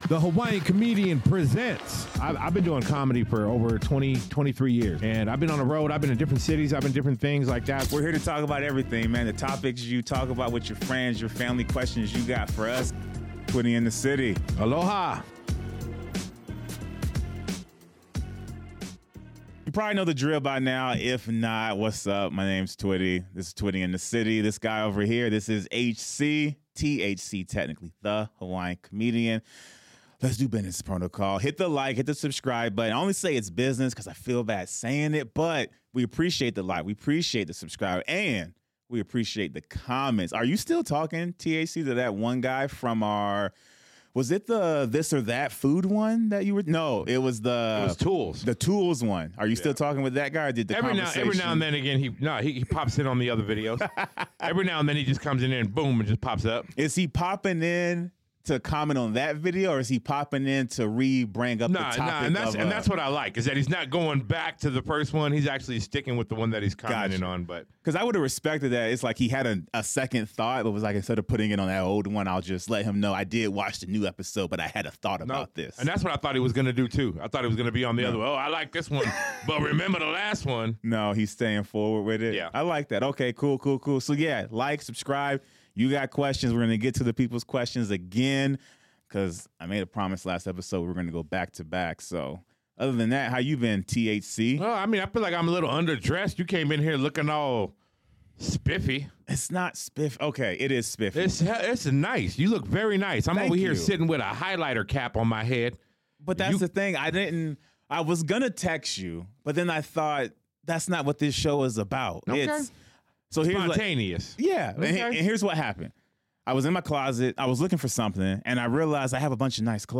They cover various topics including the challenges of sharing, dealing with loneliness, perfectionism, and being the center of attention. With plenty of humorous anecdotes and thoughtful reflections, this episode highlights the unique dynamics and perspectives that come from different childhood upbringings.